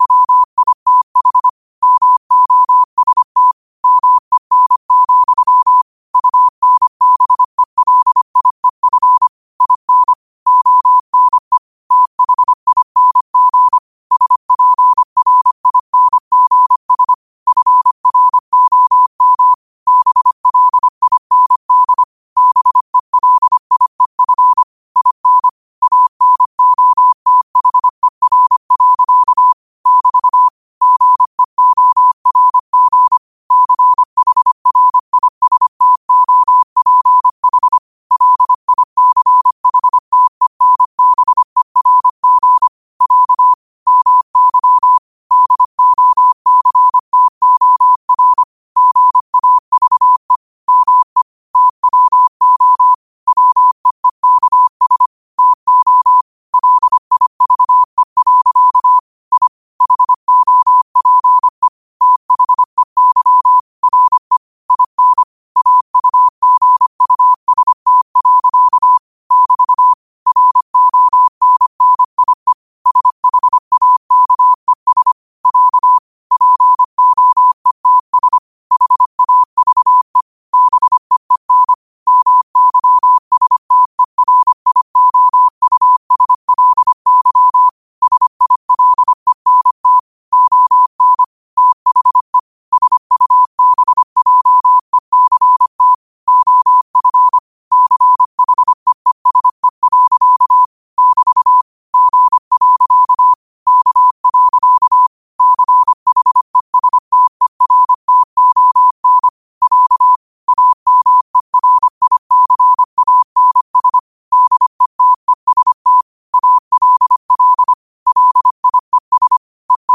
QOTD podcast provides daily podcasts of quotations in morse code, useful for studying morse code
Quotes for Sat, 13 Dec 2025 in Morse Code at 25 words per minute.